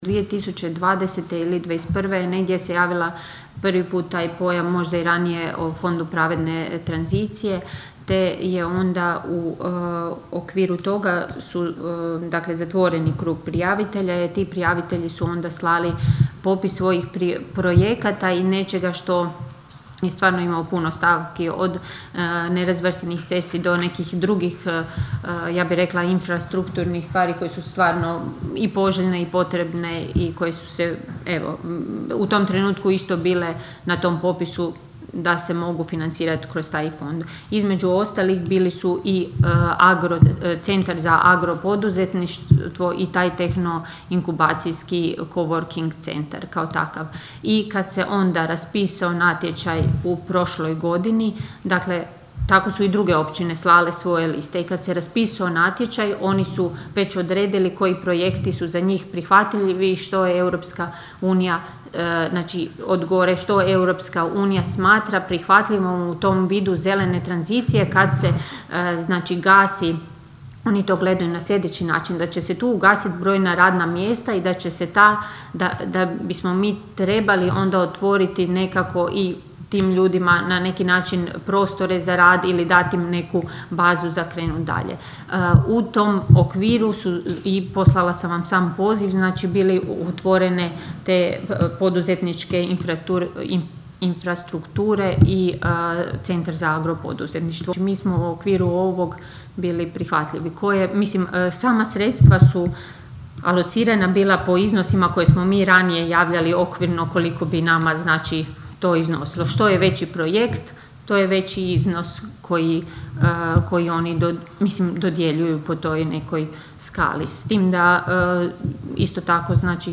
ton – Ana Vuksan), odgovorila je općinska načelnica Ana Vuksan.